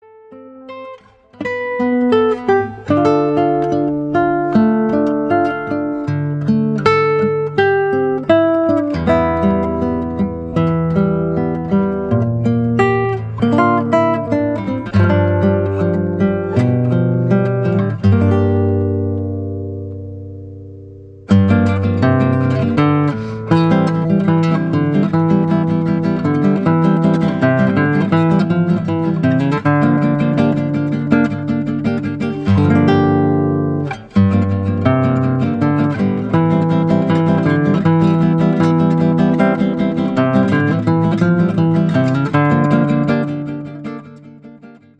Classical guitar arrangements